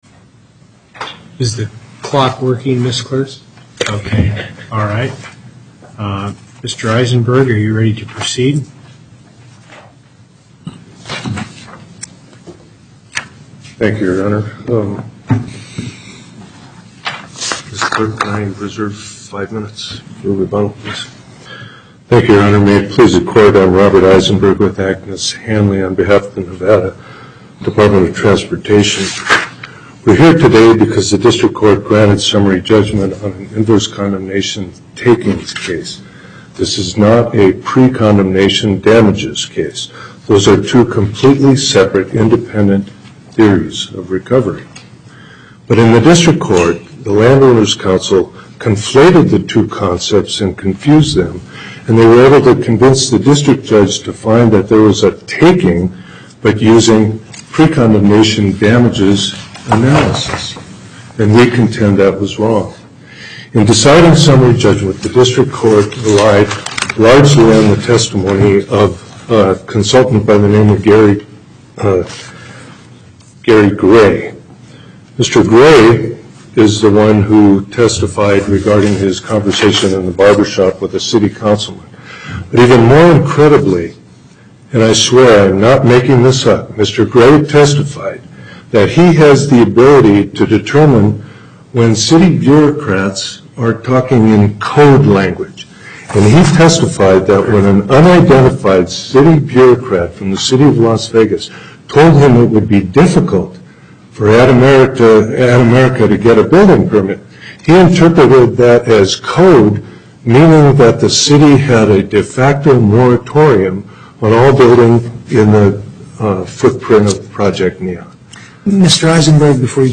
Location: Carson City Before the En Banc Panel, Chief Justice Hardesty Presiding